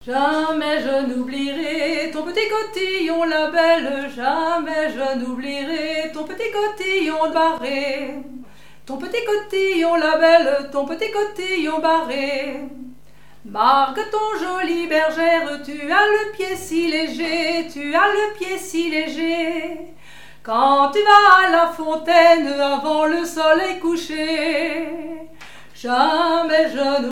danse : laridé, ridée
répertoire de chansons
Pièce musicale inédite